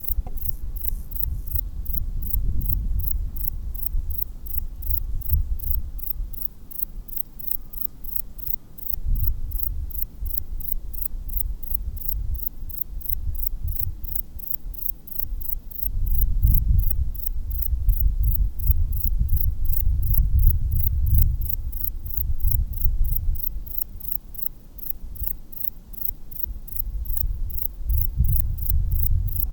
Gesang der Männchen: Mäßig laut. Reibende, schabende 4–5-silbige Verse, die minutenlang aneinander gereiht werden.
Feldaufnahme. Dresden, Dresdner Heller, 16.09.2020, 25 °C. (Zoom H6, SGH-6 Shotgun Mic Capsule, 96 khz, 24 bit).
Platycleis_albopunctata.WAV